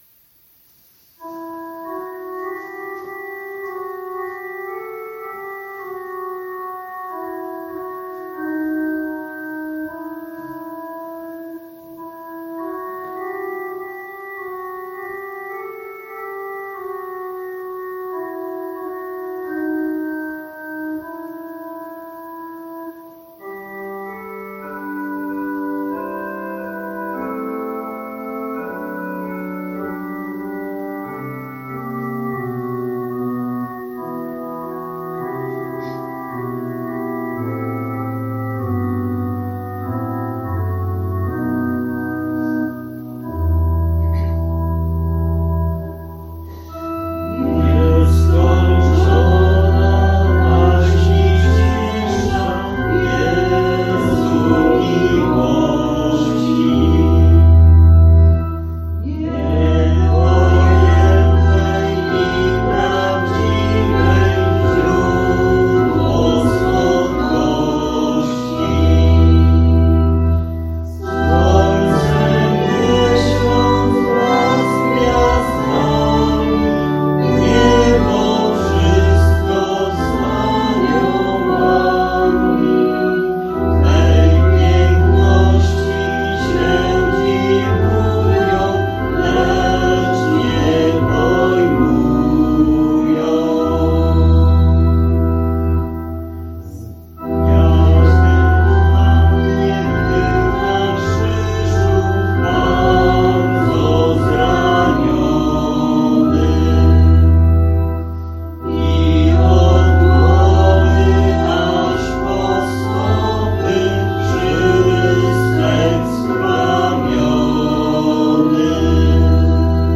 Śpiewali wierni z naszego oratorium.
Organy